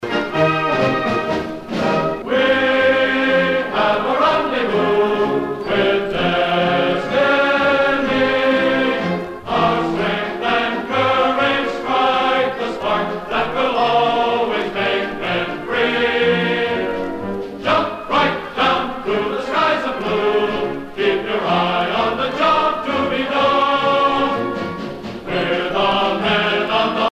Fonction d'après l'analyste gestuel : à marcher
Usage d'après l'analyste circonstance : militaire
Catégorie Pièce musicale éditée